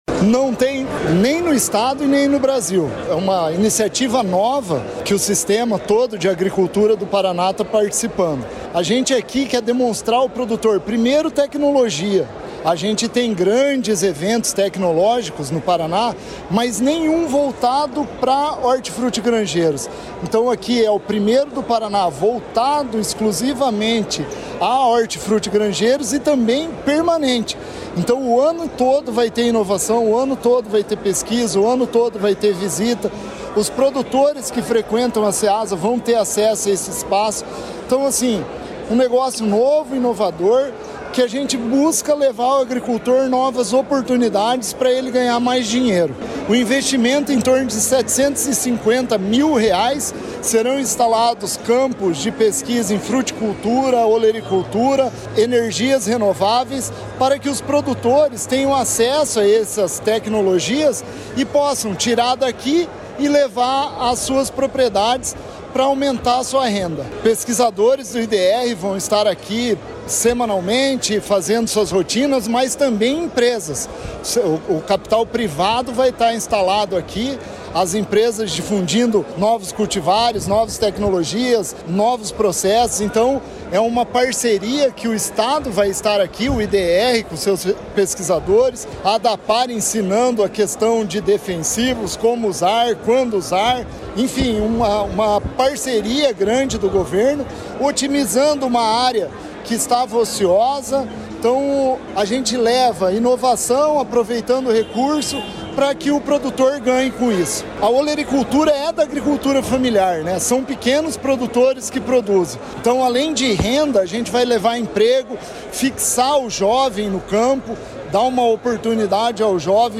Sonora do presidente da Ceasa/PR, Eder Bublitz, sobre a primeira vitrine tecnológica do Brasil para produção de hortifrutis na Ceasa de Maringá